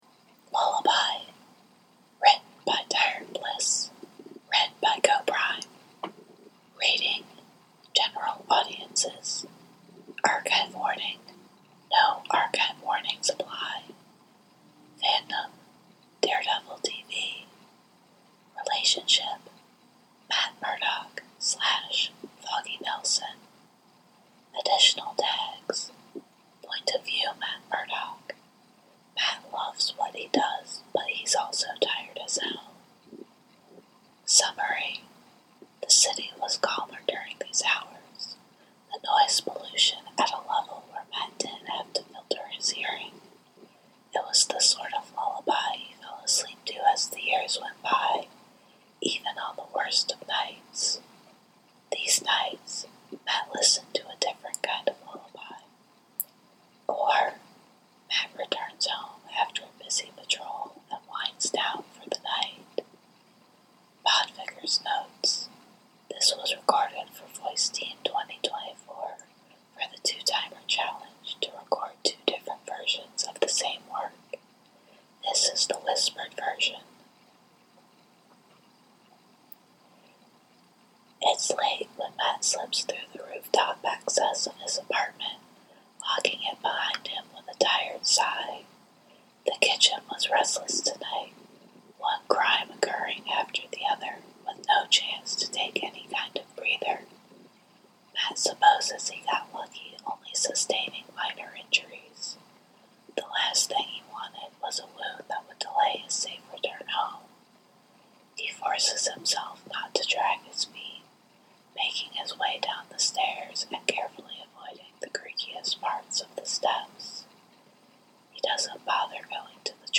One fic with two podfic versions.
Whispered style: